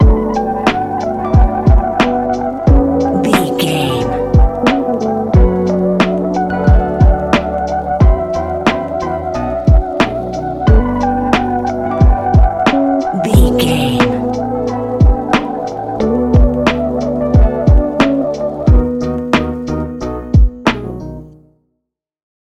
Ionian/Major
B♭
chilled
laid back
Lounge
sparse
new age
chilled electronica
ambient
atmospheric
morphing
instrumentals